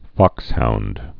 (fŏkshound)